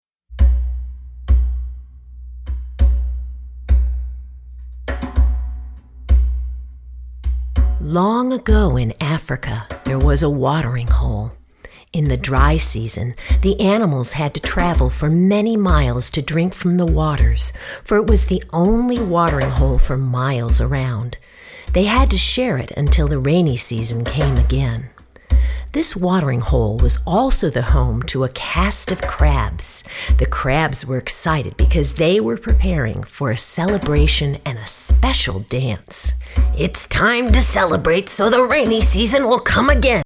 Mp3 Story Download